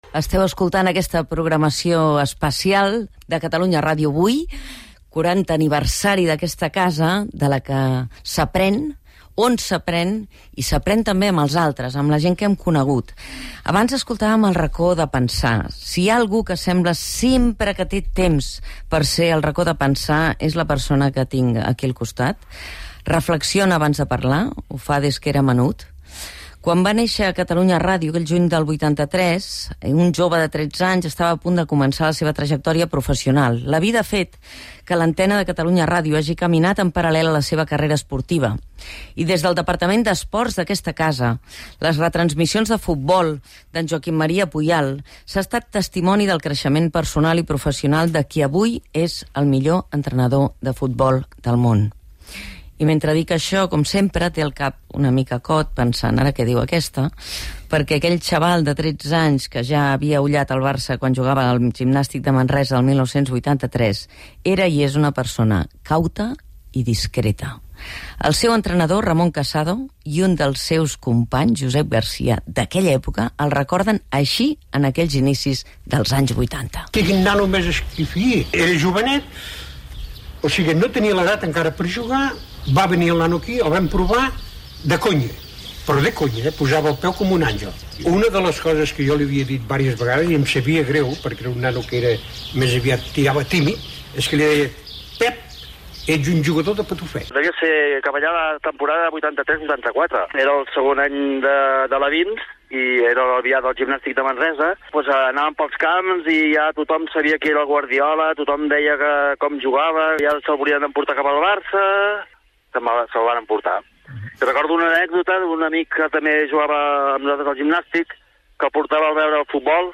Presentació i entrevista al futbolista i entrenador Pep Guardiola.